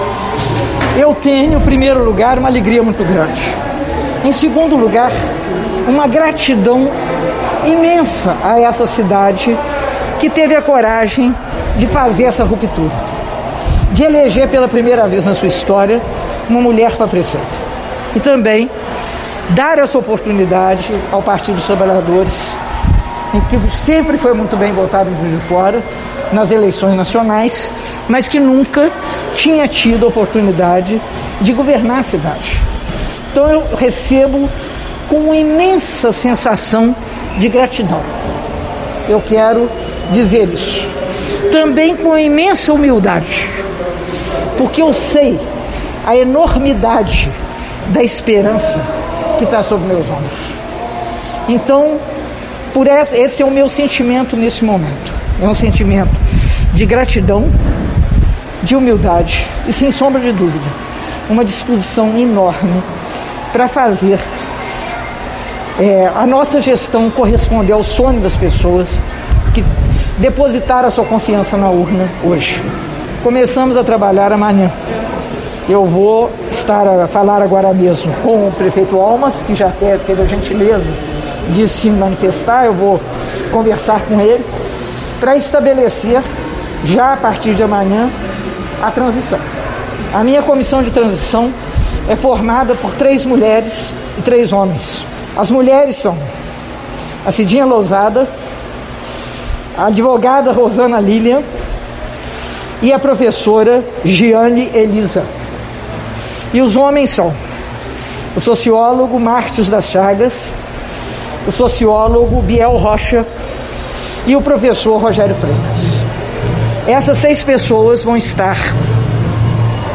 Após o resultado das urnas, ela recebeu apoiadores no Comitê da Praça da Estação e falou sobre a perspectiva para o governo.
Prefeita eleita Margarida Salomão (PT)
margarida-primeiro-depoimento-pos-eleita.mp3